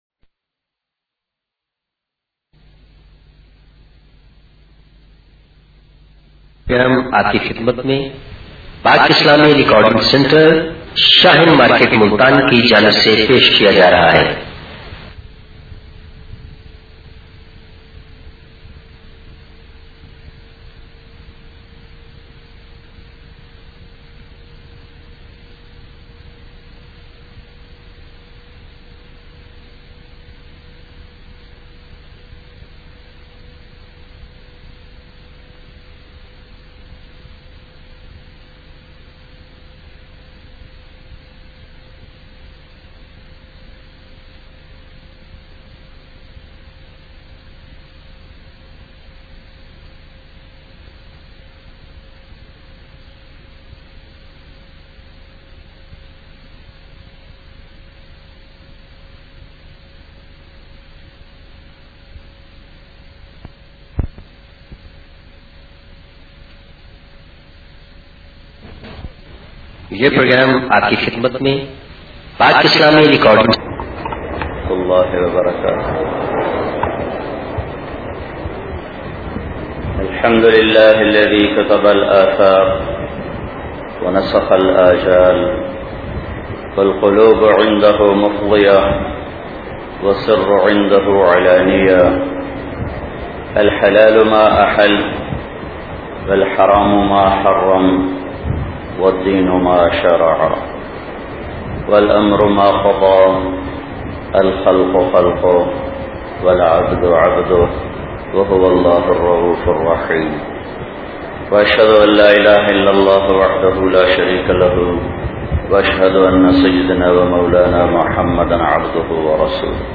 Urdu Bayan Maulana Tariq Jameel is regarded as one of the greatest Islamic preachers of our times.
Urdu Bayan